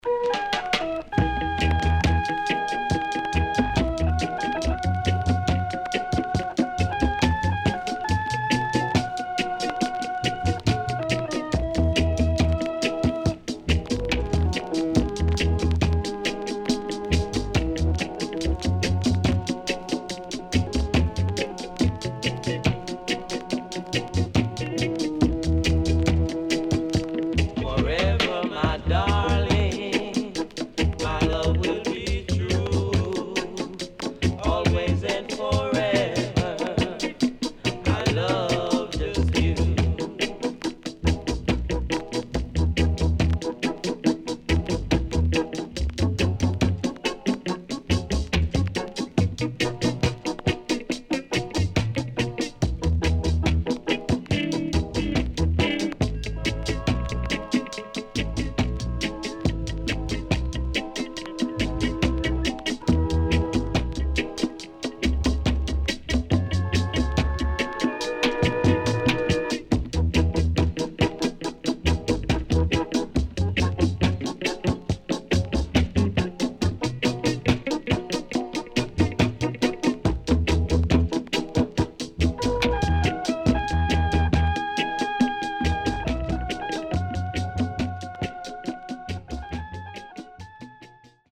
REGGAE / ROOTS
SIDE A:所々チリノイズがあり、少しプチノイズ入ります。